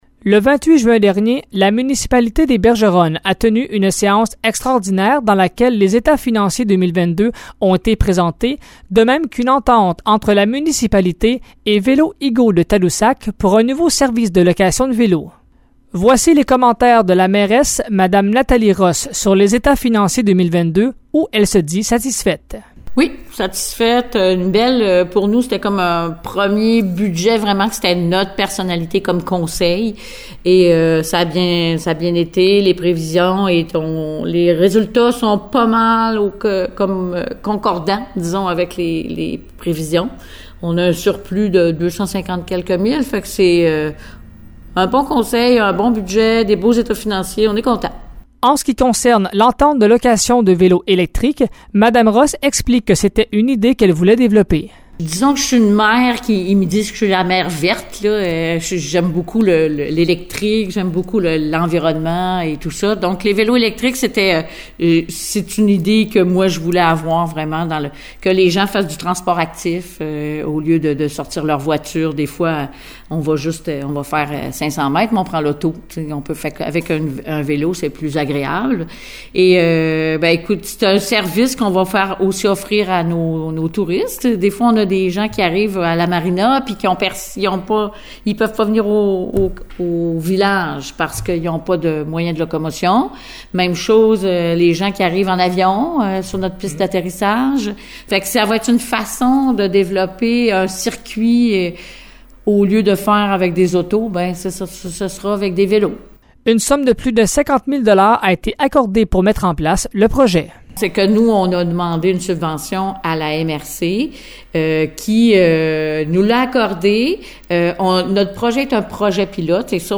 Voici le reportage